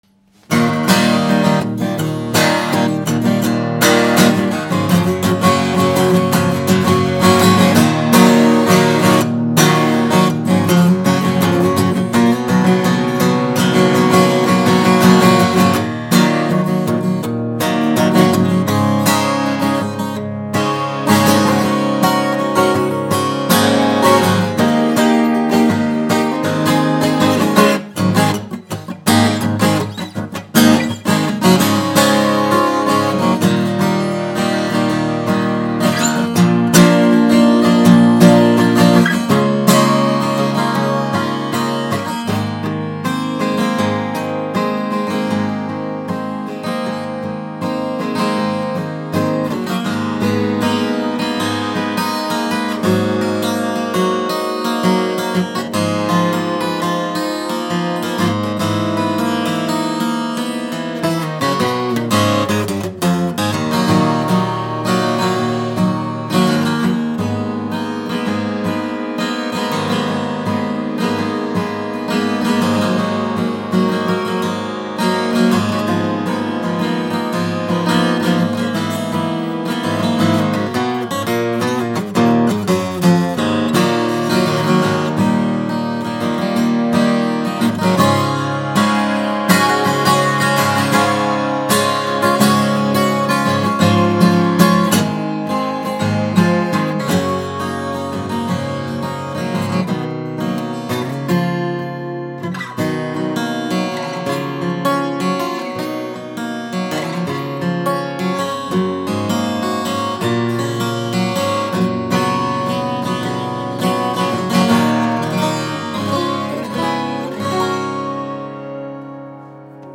Martin HD12-28 12-string
This Martin HD12-28 Re-Imagined offers undeniably the unique sound and sustain of a Rosewood 12 string.
Pairs of octave strings and drone notes are positioned for maximum comfort and playability, and create a well-rounded, focused tone with depth and sustain that will chime for days.